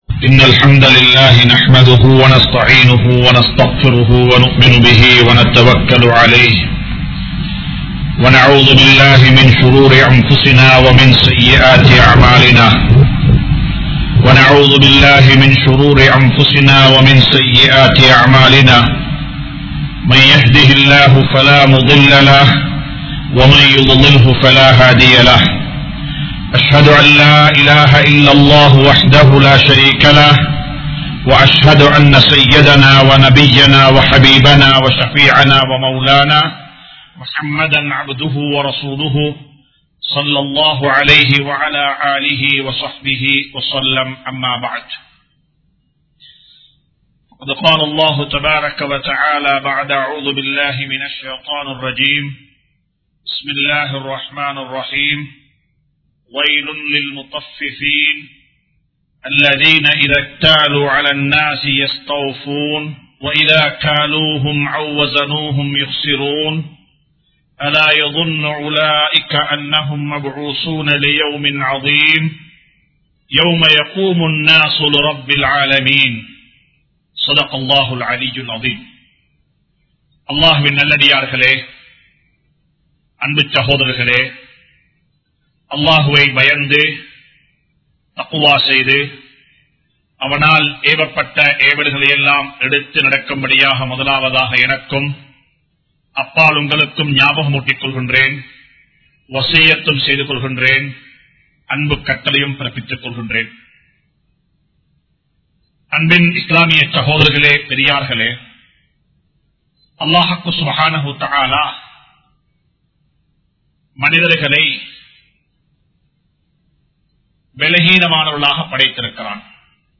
Pirarin Soththukkalai Apakarikkaatheerkal!(பிறரின் சொத்துக்களை அபகரிக்காதீர்கள்!) | Audio Bayans | All Ceylon Muslim Youth Community | Addalaichenai